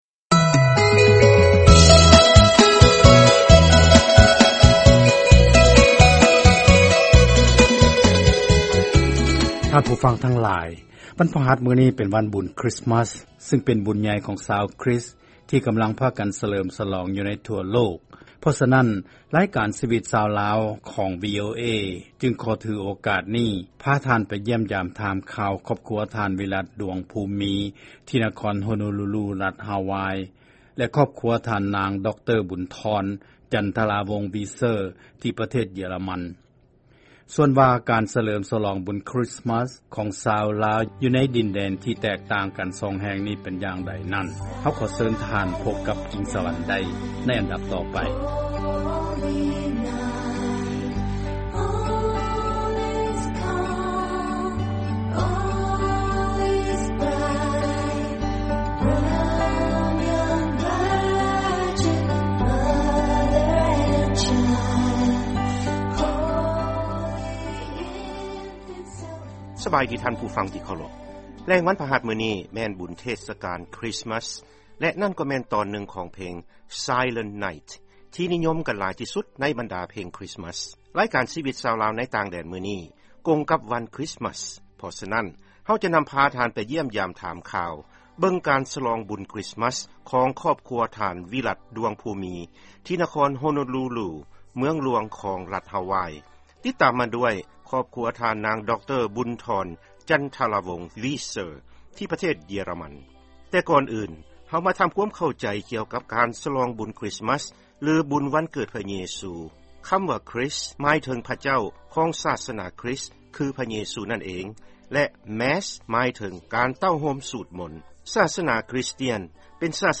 ຟັງການສຳພາດ ກ່ຽວກັບວັນເທດສະການບຸນຄຣິສມັສ ທີ່ລັດຮາວາຍ ແລະປະເທດເຢຍຣະມັນ